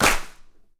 pcp_clap09.wav